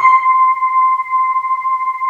Index of /90_sSampleCDs/USB Soundscan vol.28 - Choir Acoustic & Synth [AKAI] 1CD/Partition D/08-SWEEPOR